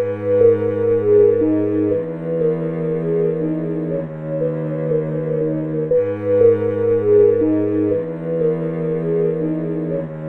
Category: Cinematic Ringtones